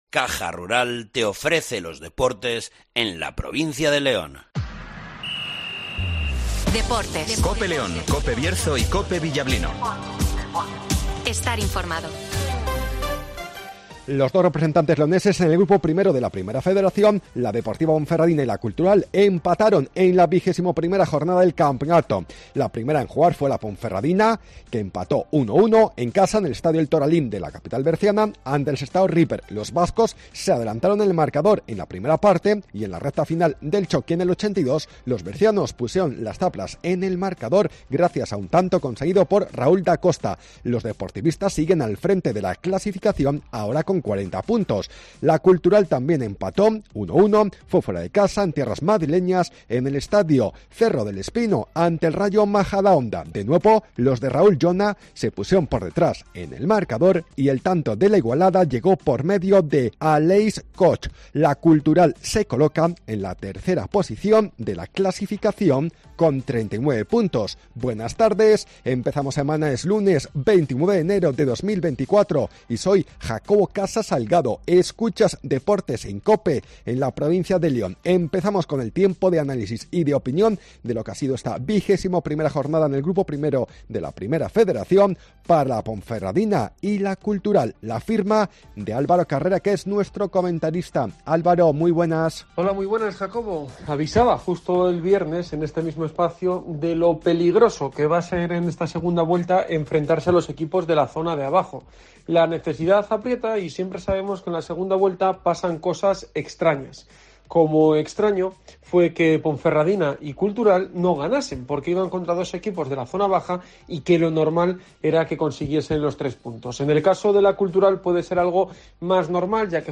Deportes León